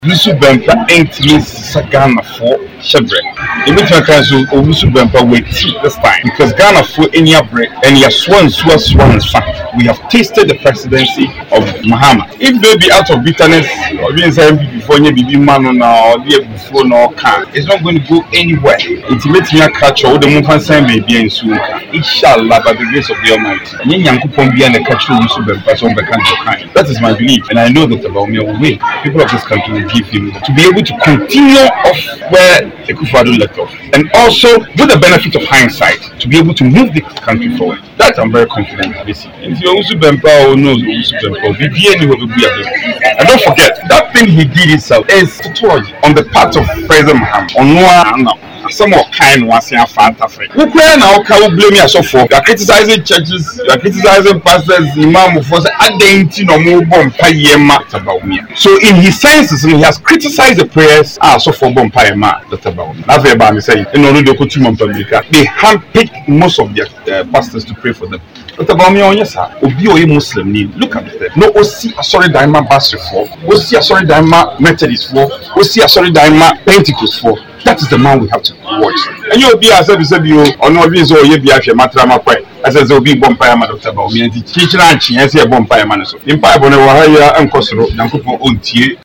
Speaking to the media after commissioning Sakyikrom Health Center and renovating Nsawam old Hospital’s lavatory, the MP said Rev Owusu Bempah’s prophecy to the former President weeks ago will never be fulfilled because he believes God did not speak to him.